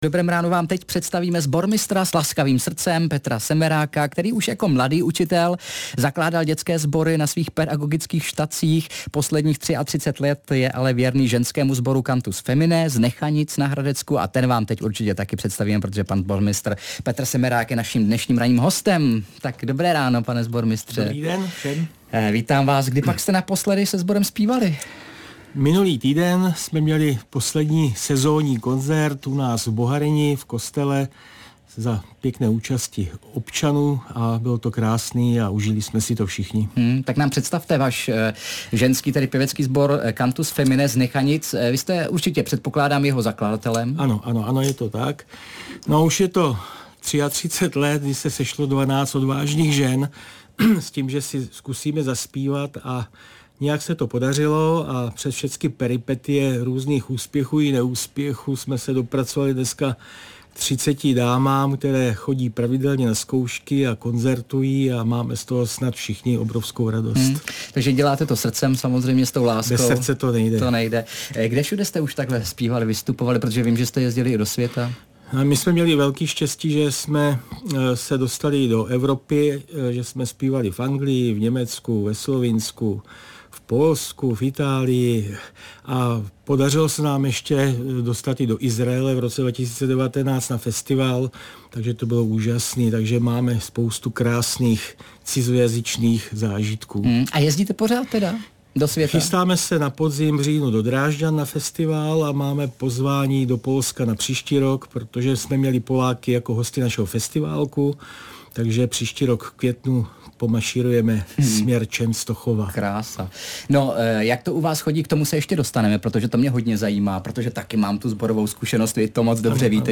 Host ve studiu: Před 33 lety se sešlo 12 odvážných žen, dnes už ve sboru Cantus Feminae z Nechanic zpívá hned 30 dam - 16.06.2025